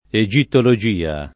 egittologia [ e J ittolo J& a ] s. f.